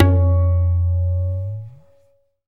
TABLA LONG-S.WAV